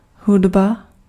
Ääntäminen
France: IPA: [la my.zik]